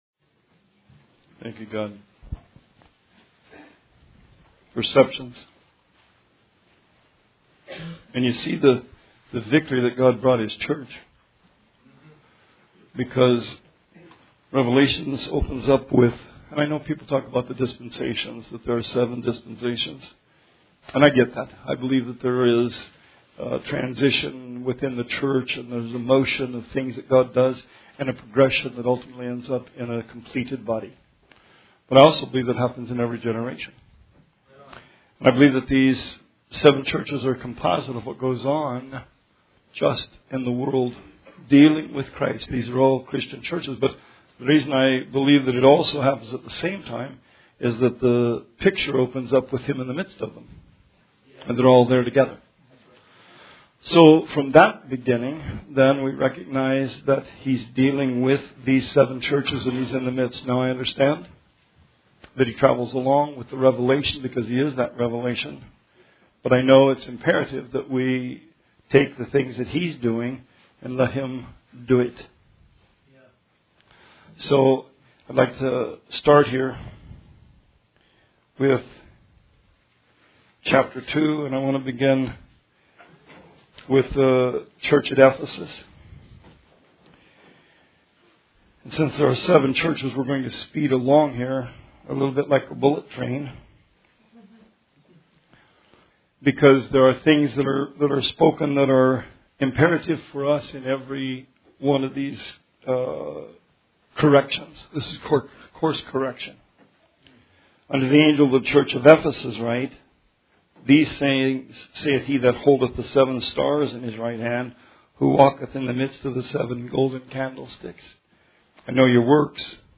Sermon 3/3/19